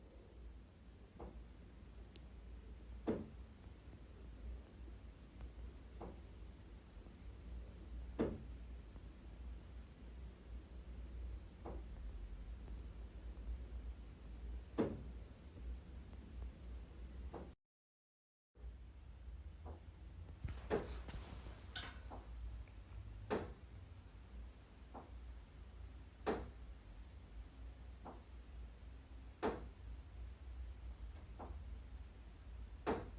A chaque fois qu'il prend une douche, j'entends des bruits de claquement contre le mur. C'est très très bruyant mad.
Bruit tuyauterie
Je précise que le bruit commence 10-20 secondes après qu'il démarre sa douche, dure touuuuut le temps de sa douche (de + en + fort) et s'estompe petit à petit quand il termine.